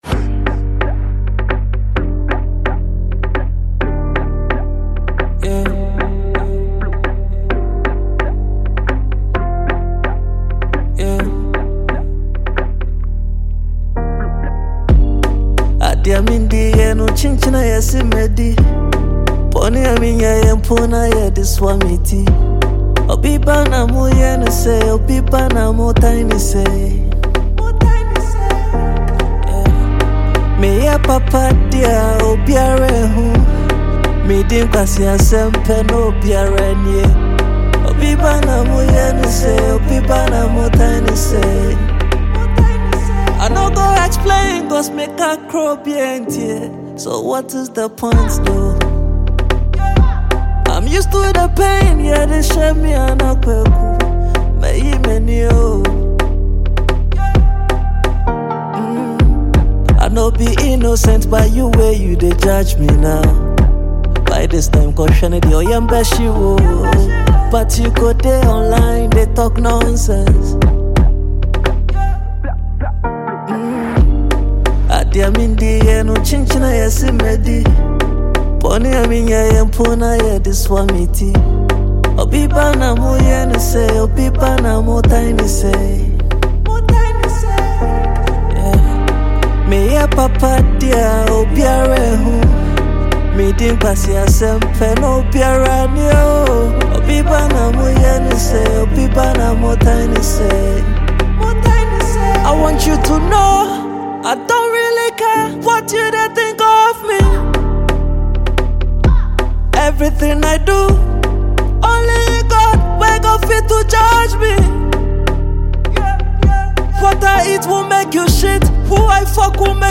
Ghanaian singer and songwriter